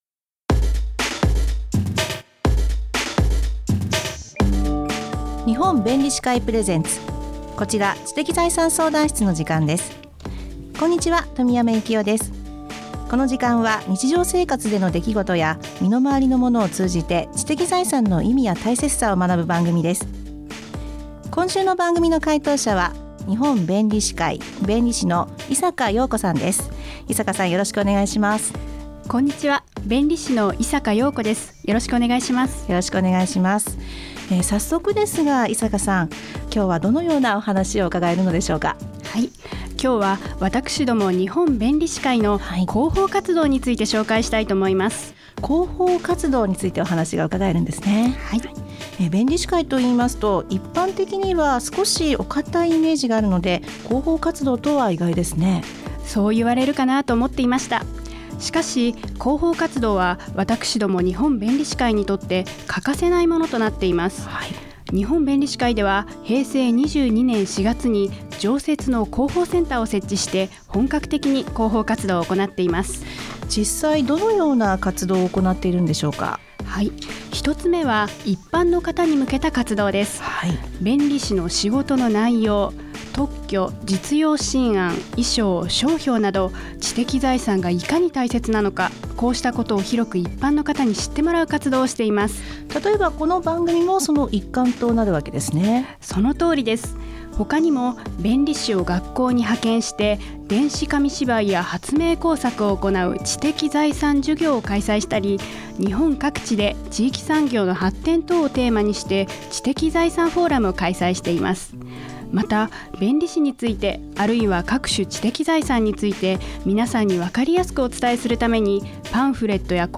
日本弁理士会提供ラジオ番組にて放送しました。
知的財産にまつわる質問に弁理士が答えます！